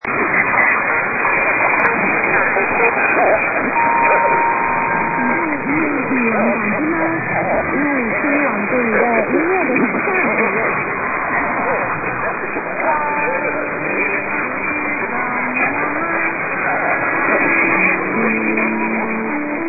> > 1557 TAIWAN, presumed with lively pop tunes 1355-1410+ nonstop except
> > for 3+1 pips & brief Chinese anmt by woman at 1400. Still going strong